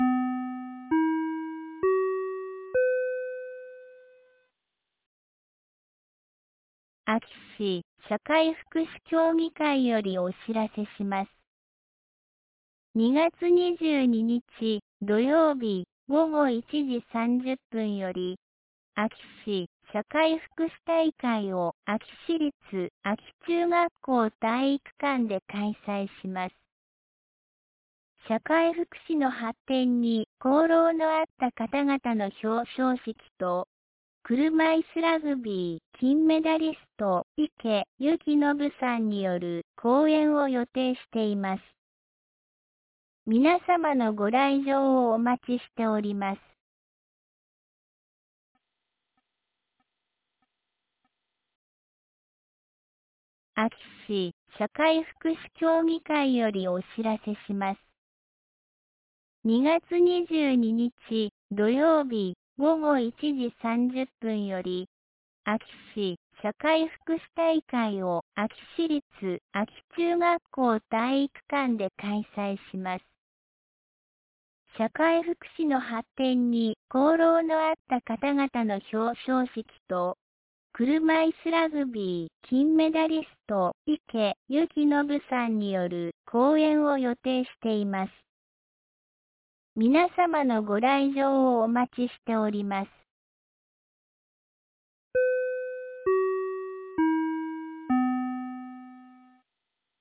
2025年02月20日 15時01分に、安芸市より全地区へ放送がありました。